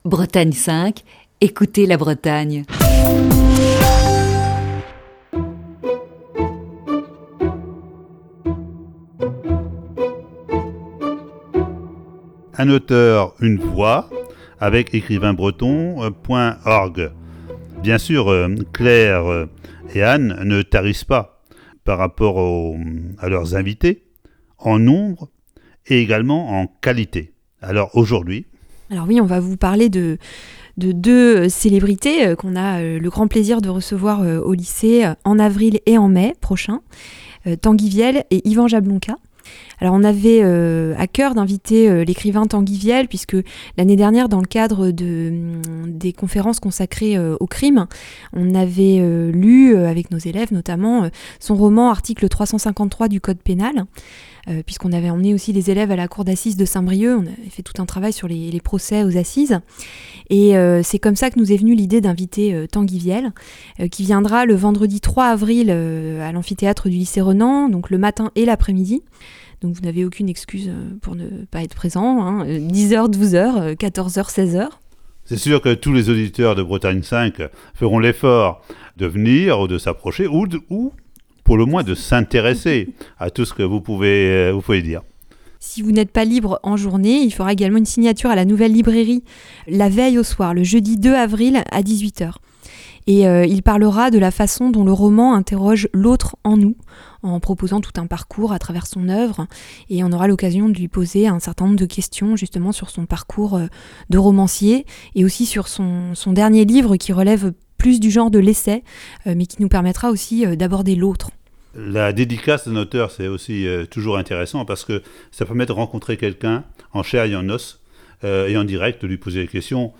Ce lundi, sixième partie de cet entretien.